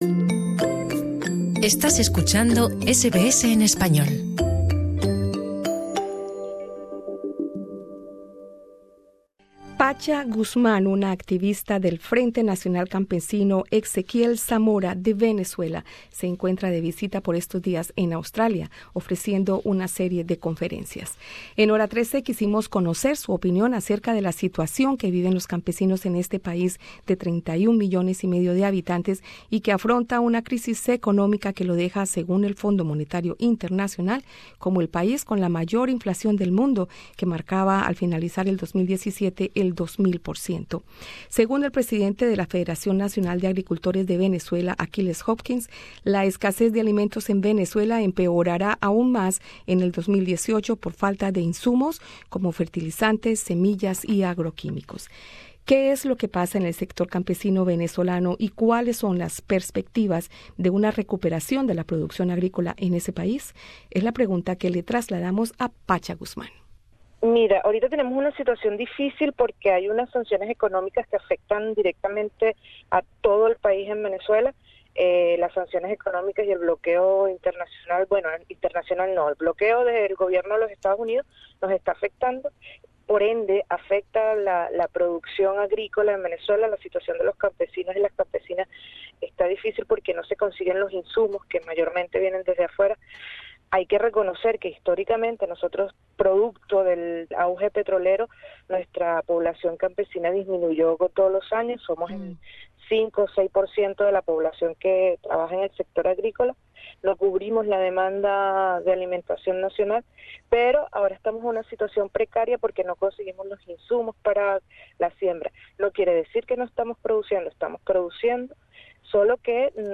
Escucha en nuestro podcast la entrevista.